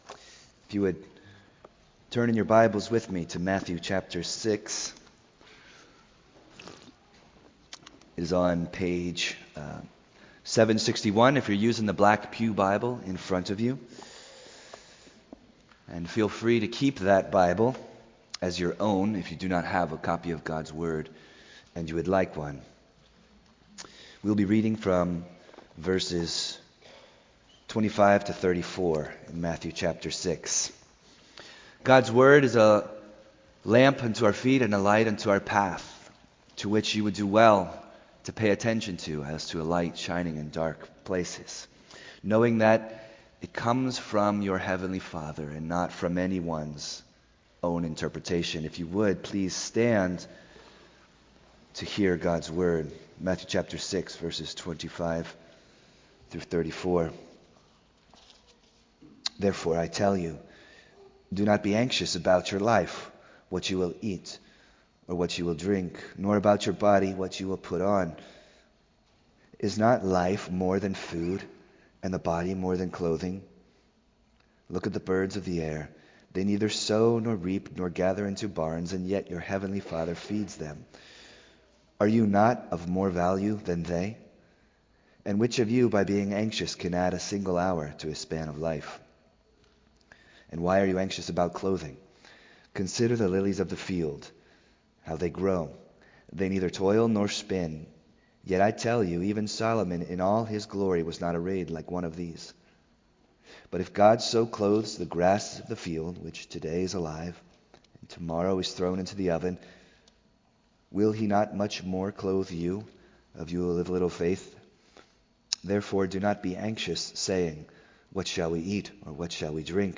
Series: The Sermon on the Mount